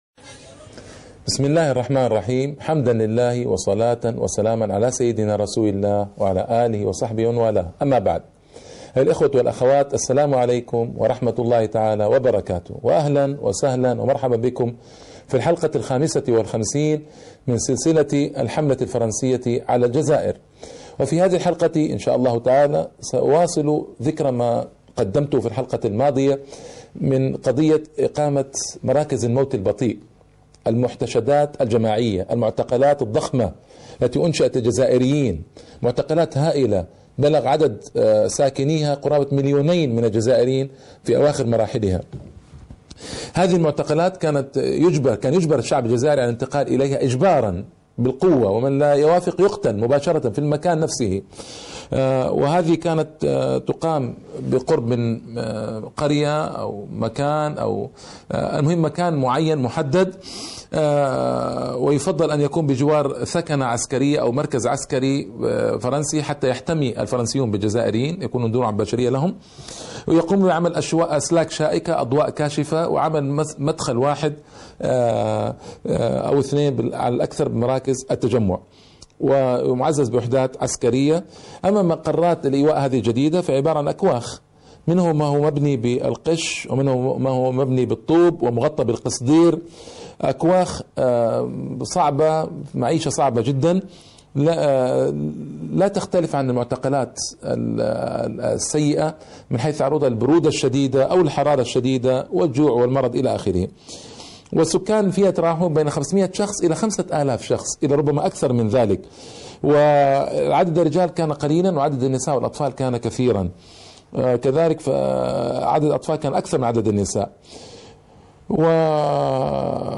الكتب مسموعة